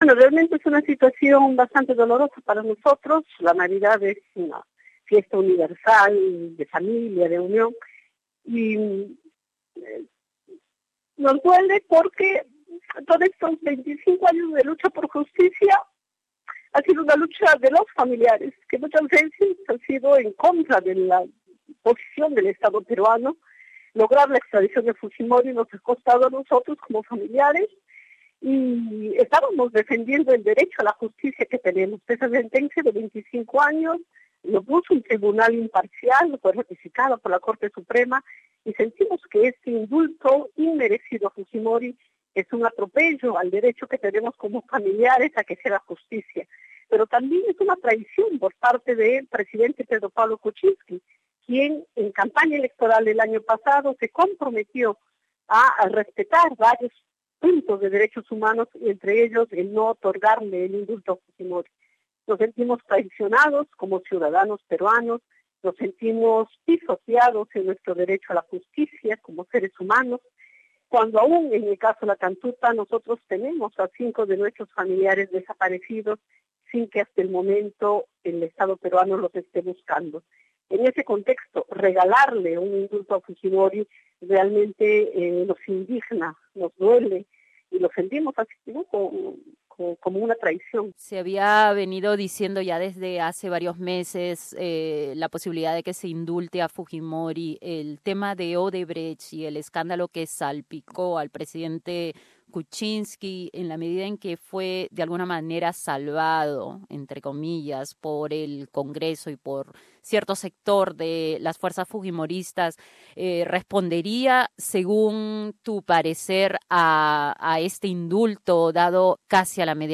entrevista con radio SBS